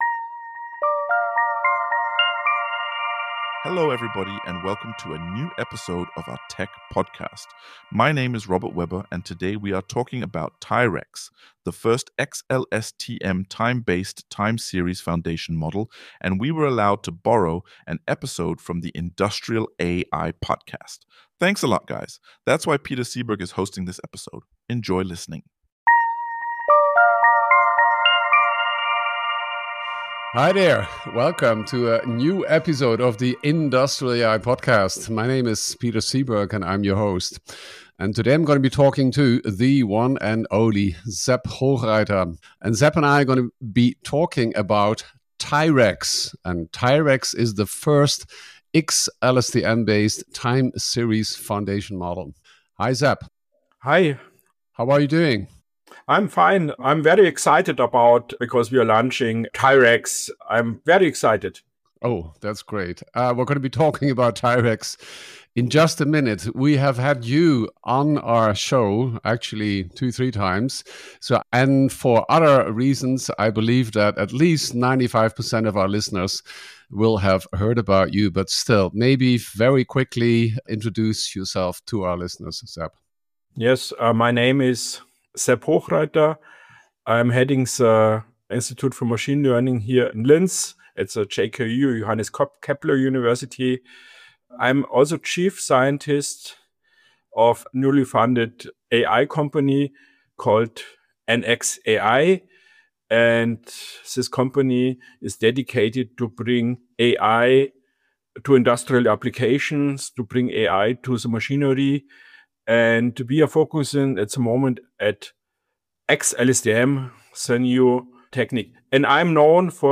In the interview Sepp Hochreiter explains how he build the model, what state tracking is, how he came up with the devices and what the next steps are.